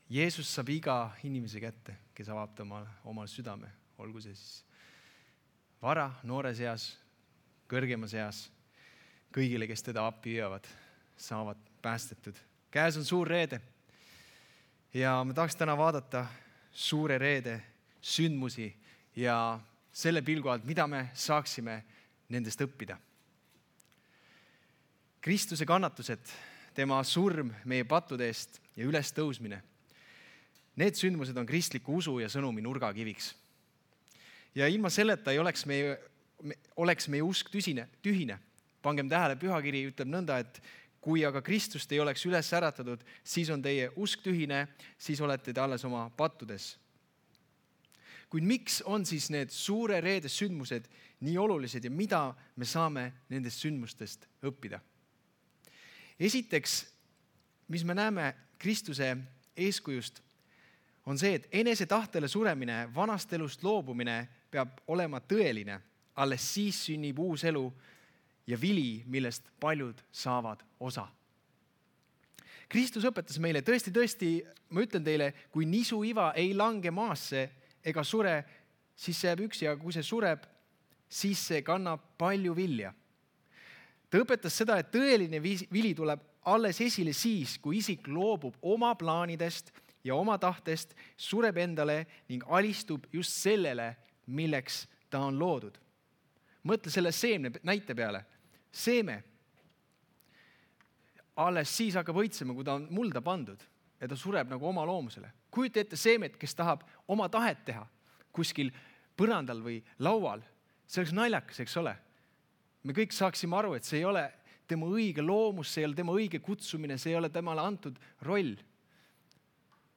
Jutlused - EKNK Toompea kogudus
02.04.2021 Suure Reede jutlus